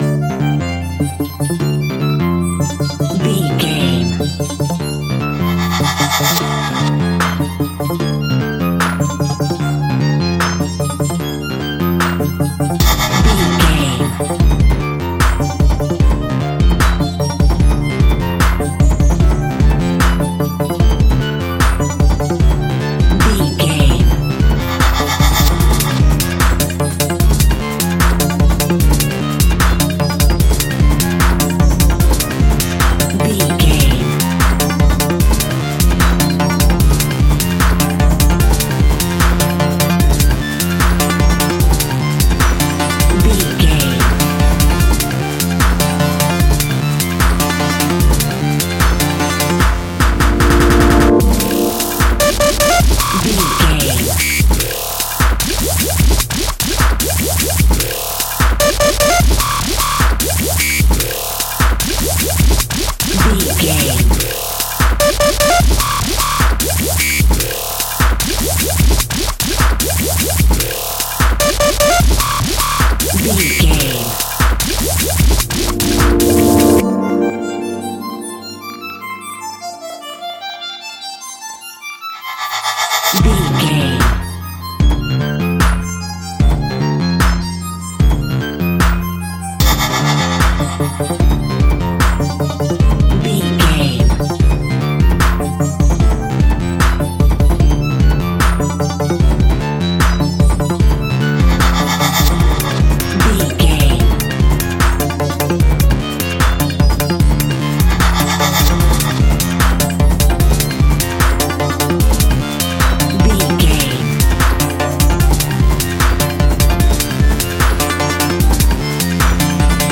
Epic / Action
Fast paced
Aeolian/Minor
aggressive
dark
driving
intense
disturbing
drum machine
synthesiser
breakbeat
synth leads
synth bass